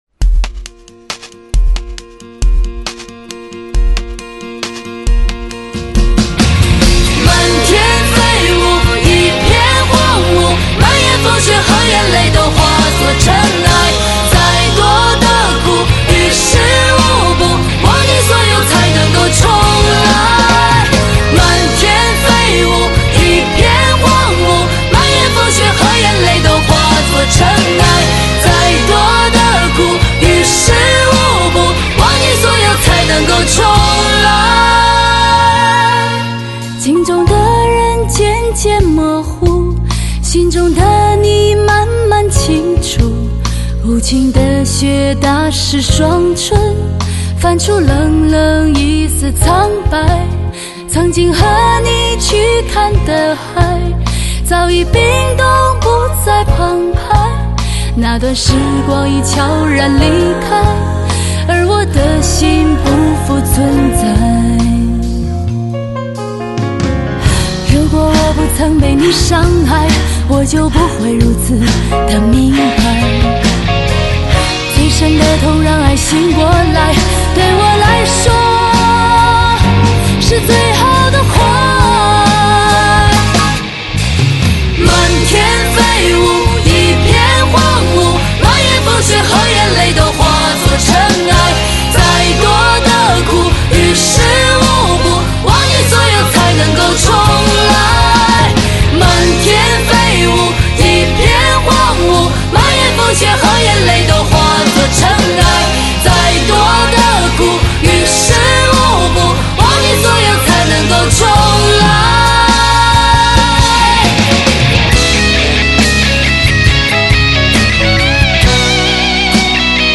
演绎上很有力度和气势
没想到这么瘦弱的小姑娘有这么强的爆发力